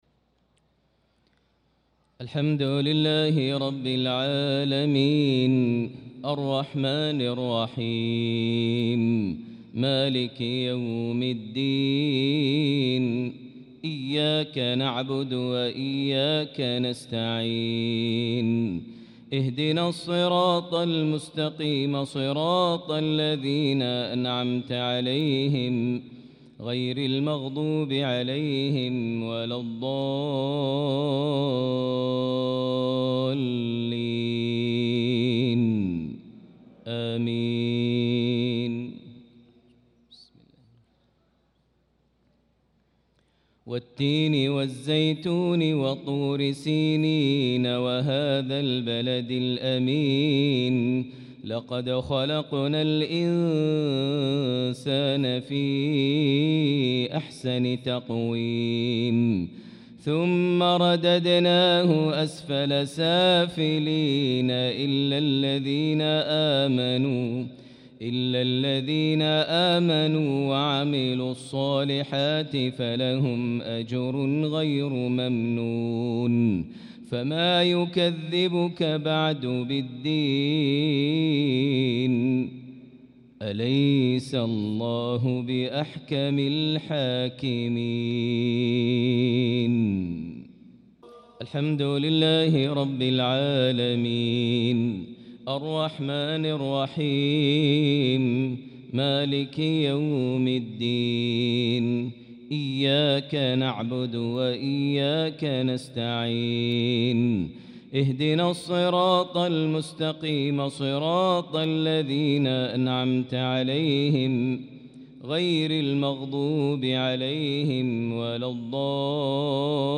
صلاة المغرب للقارئ ماهر المعيقلي 6 شوال 1445 هـ
تِلَاوَات الْحَرَمَيْن .